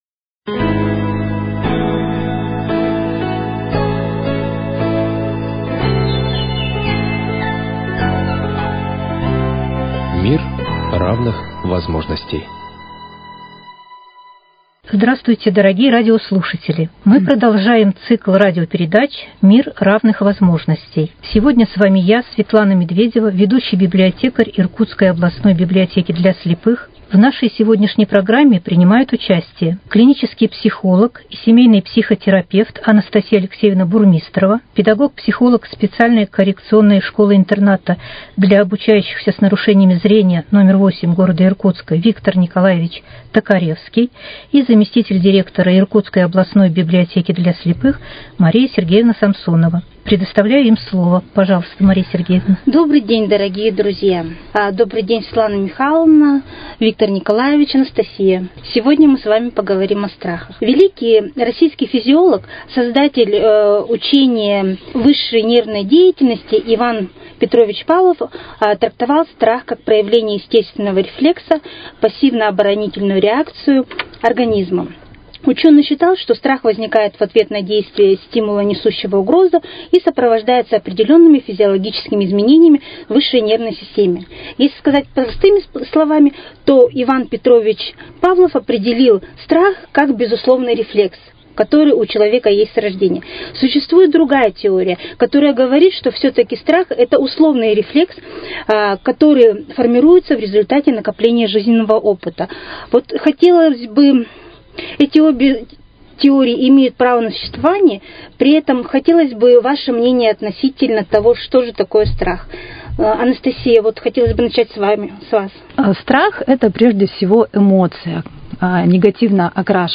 О природе страха, о причинах его появления, о том, как страх влияет на нашу жизнь рассуждали в студии Иркутского радио